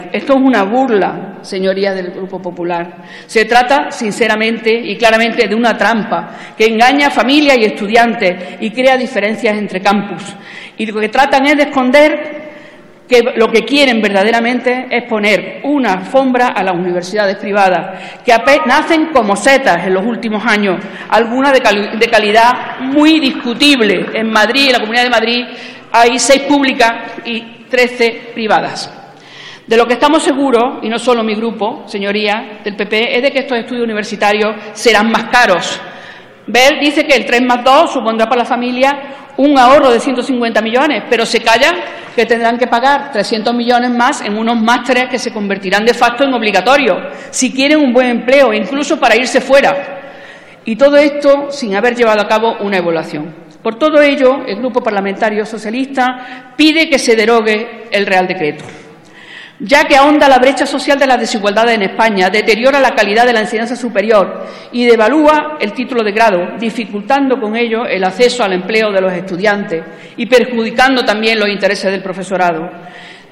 Fragmento de la intervención de Angelina Costa en el pleno del 18/2/2015 defendiendo una moción contra el decreto 3+2 del ministro de Educación